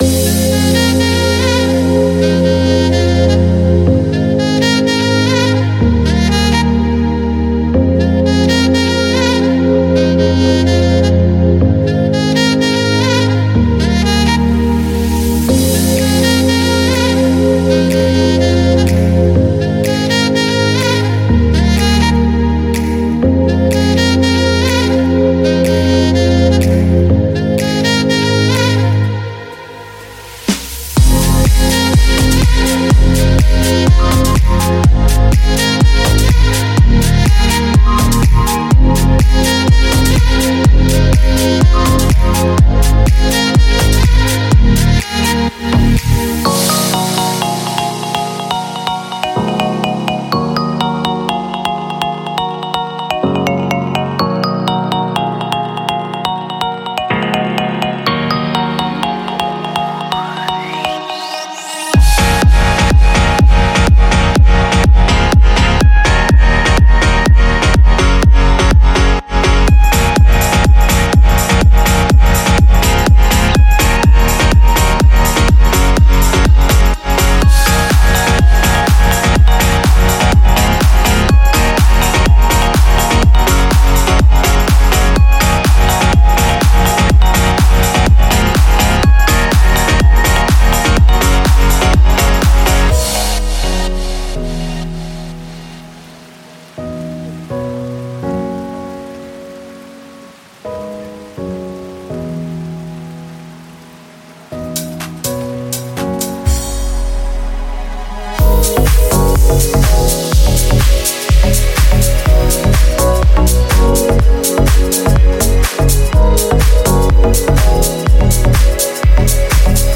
矮矮胖胖的深屋，迪斯科舞会的节奏，飘逸的乐器引线和经典的闪光音调带回了时令沙滩的声音。
• 鼓声>厚正弦乐，老式的踩hi，有机的咔嗒声和拍手等等。
• 鼓圈>全脂室内节拍，热带鼓风和异国情调的calypso节奏确保在地板上发挥作用。
• 旋律循环>老式风琴，丰富的复音垫和令人振奋的钢琴。
• 低音循环>美味的低音，肮脏的节奏和充满凹槽的低端摆动。
• 吉他环>时髦的手感，轻盈的弹奏和经过处理的合成吉他。
• FX> 25 x节奏同步的合成器样式提升板，白噪声冲击和郁郁葱葱的激光器。